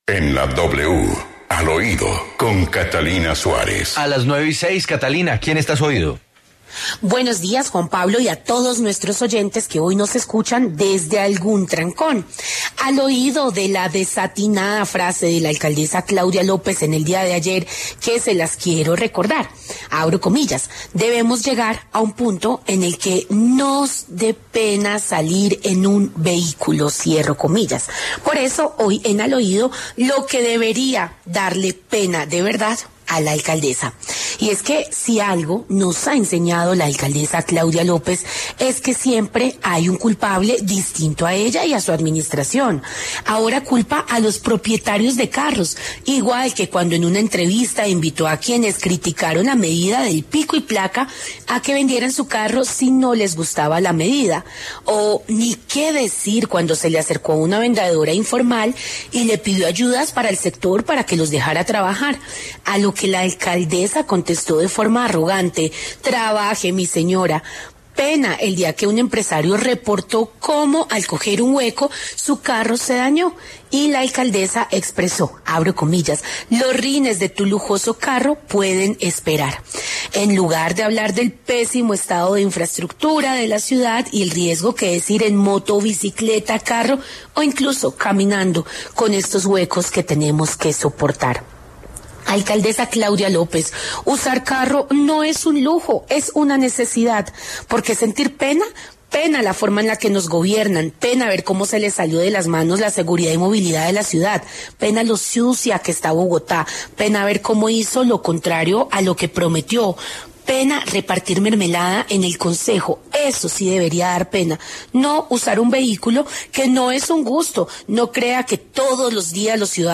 Editorial Al Oído